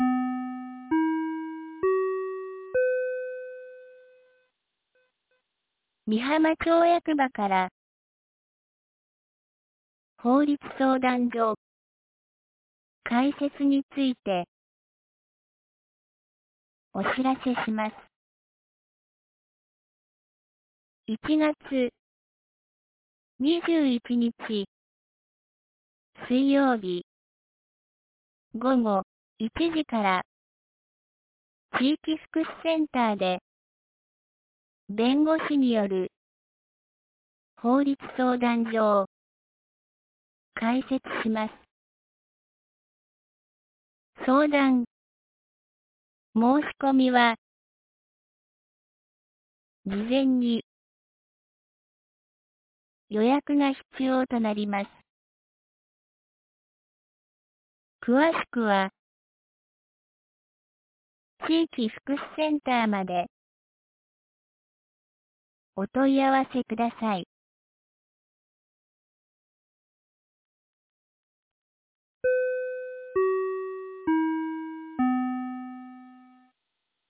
美浜町放送内容 2026年01月07日07時46分 （町内放送）法律相談所開設 | 和歌山県美浜町メール配信サービス
2026年01月07日 07時46分に、美浜町より全地区へ放送がありました。